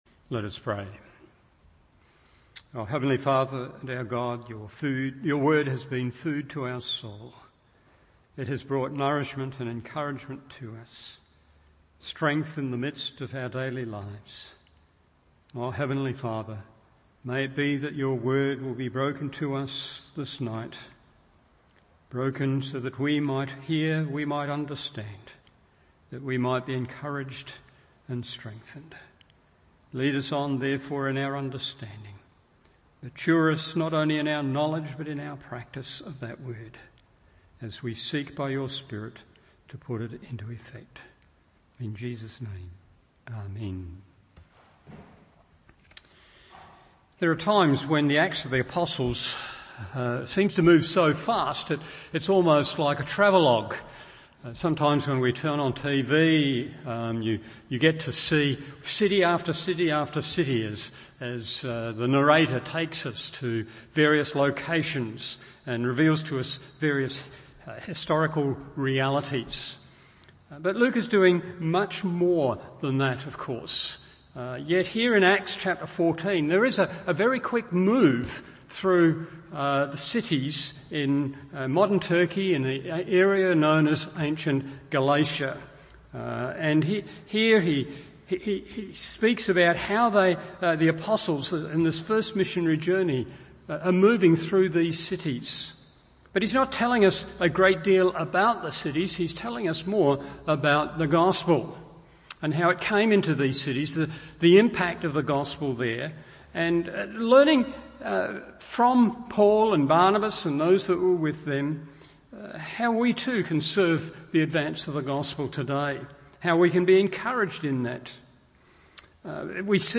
Evening Service Acts 14:1-22 1. The Gospel’s Advance 2. The Gospel’s Rejection 3. The Gospel’s Response…